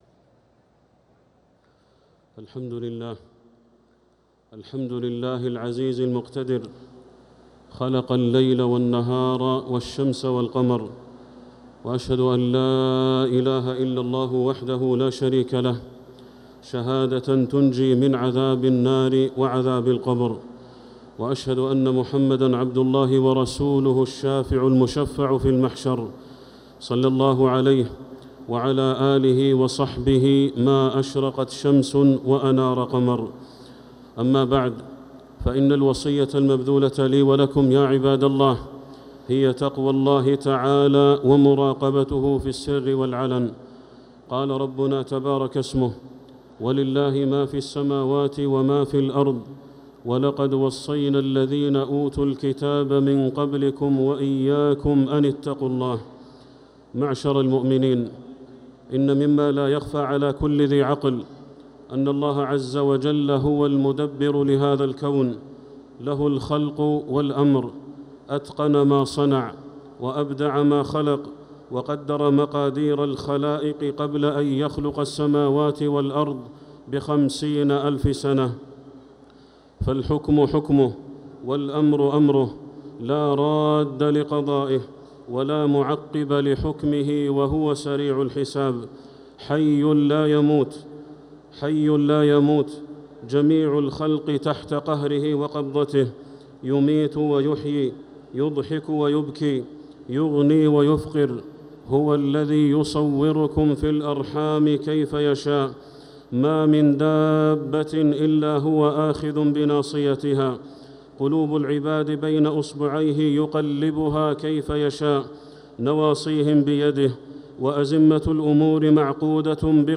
خطبة الخسوف 15 ربيع الأول 1447هـ > الكسوف 🕋 > المزيد - تلاوات الحرمين